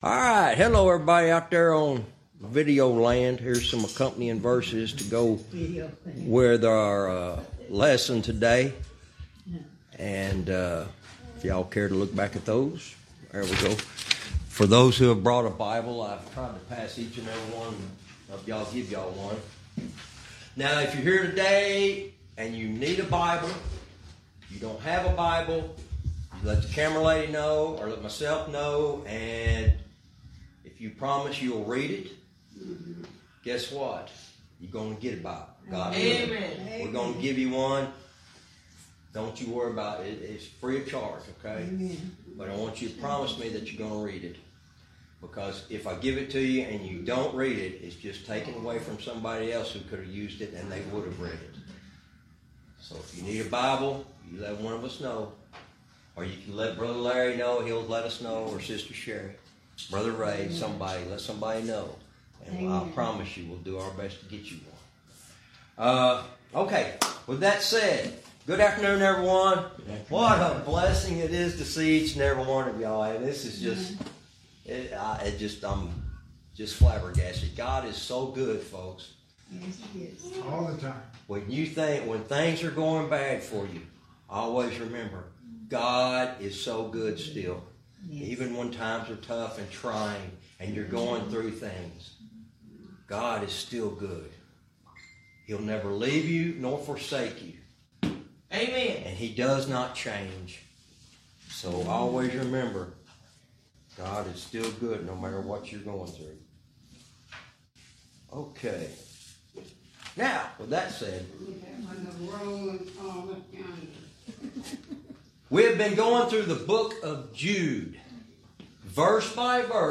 Verse by verse teaching - Jude lesson 100 verse 22